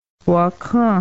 W - Wa Wakan Holy Wa-Kaun